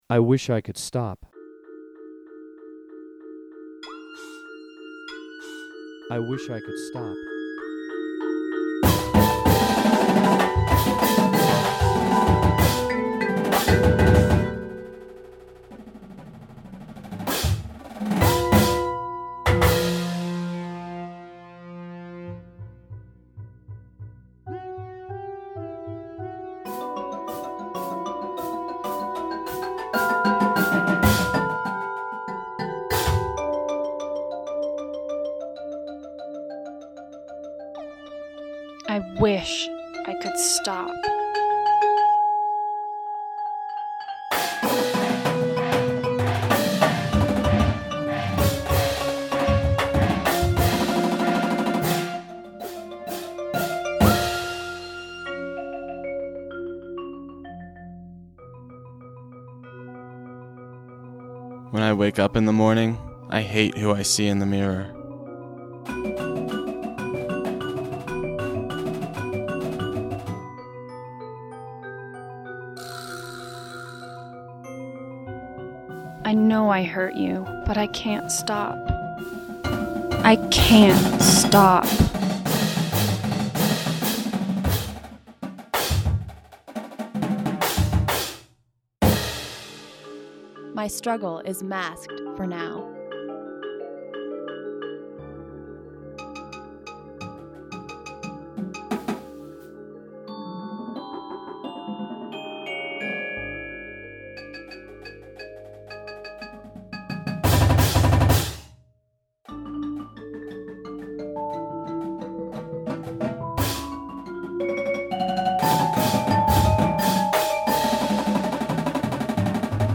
modern Indoor Percussion Show